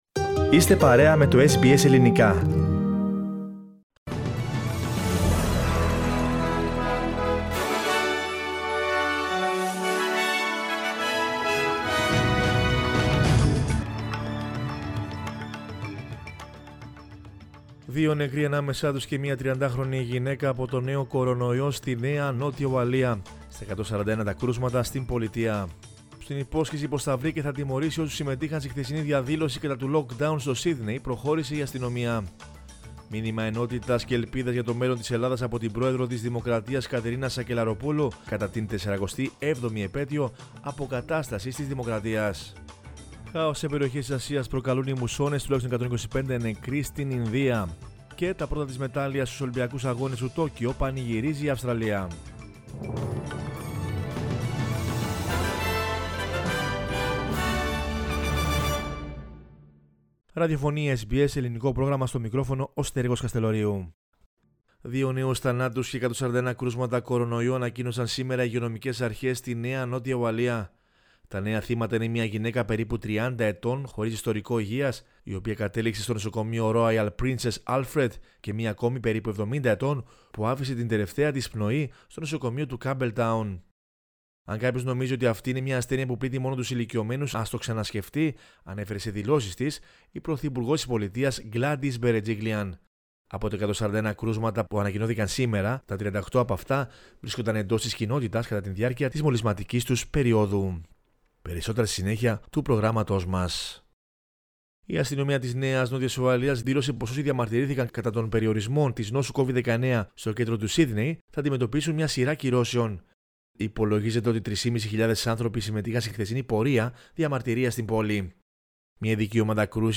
News in Greek from Australia, Greece, Cyprus and the world is the news bulletin of Sunday 25 July 2021.